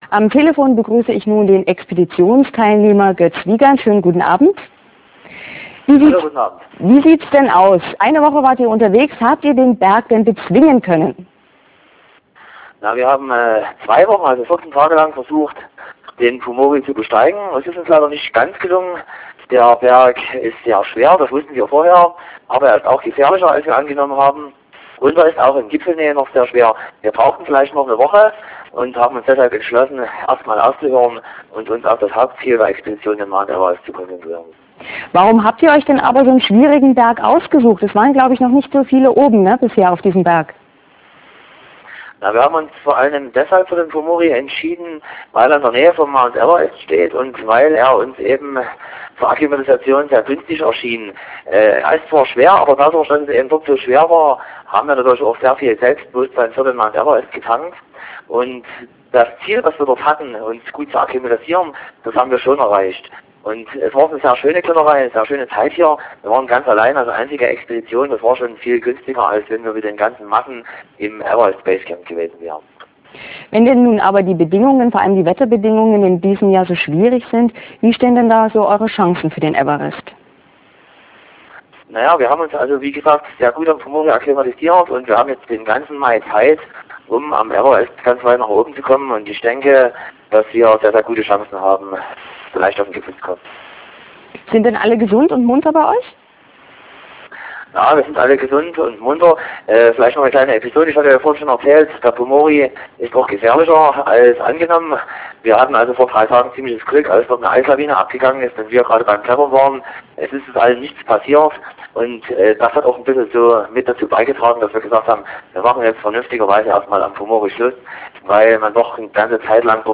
Drittes Telefonat via Satellitentelefon ins